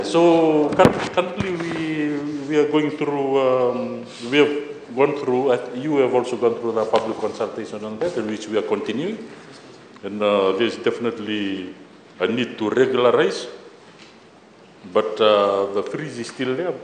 During a parliamentary address, Minister Ro Filipe Tuisawau emphasized that they are focusing on reviewing regulations associated with this issue.